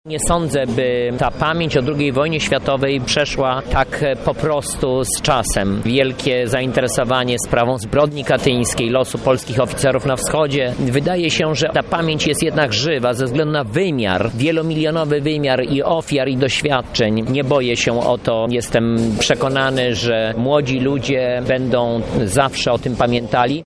-II Wojna Światowa wryła się ludziom w pamięć o wiele bardziej ponieważ są jeszcze ciągle żyjący świadkowie i wiele przekazów – mówi Jacek Sobczak, członek zarządu Województwa Lubelskiego